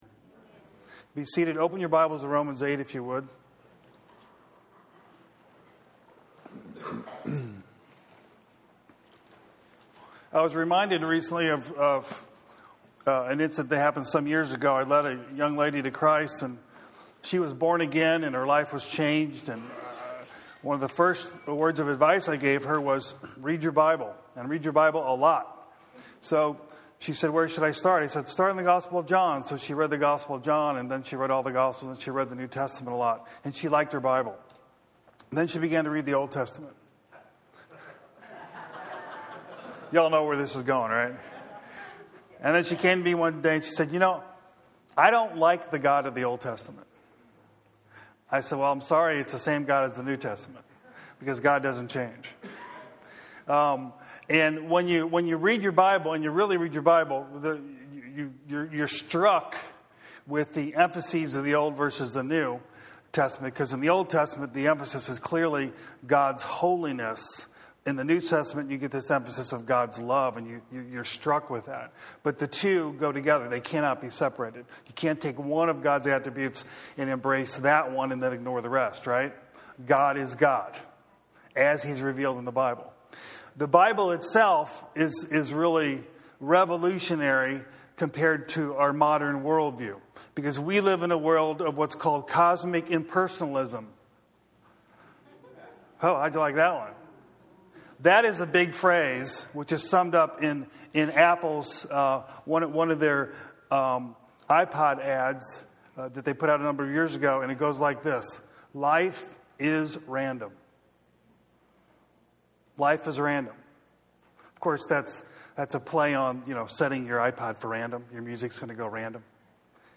Exposition on Romans 8:28: God governs all things and permits sufferings in our lives to conform us to the image of His Son Jesus Christ.